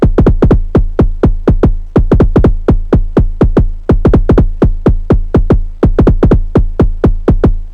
• kick and perc analog techno volcadrum 2 - 124 Em.wav
kick_and_perc_analog_techno_volcadrum_2_-_124_Em_8Vx.wav